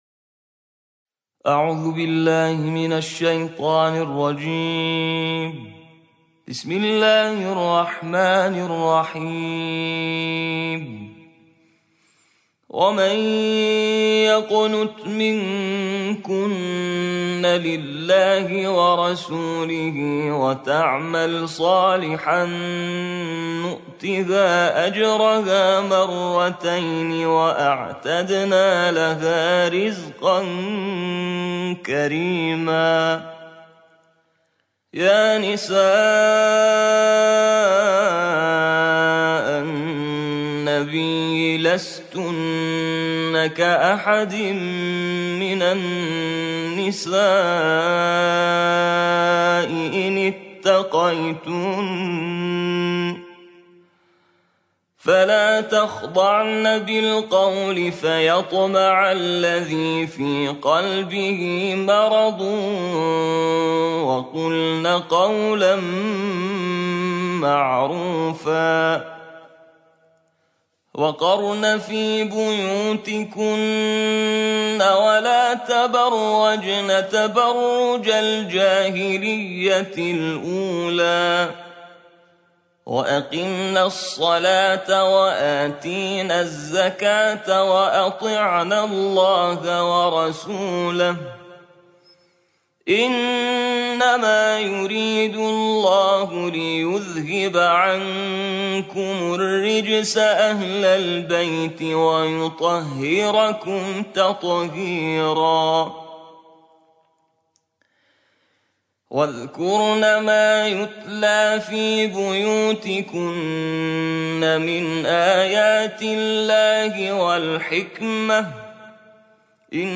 صوت | ترتیل جزء 22 قرآن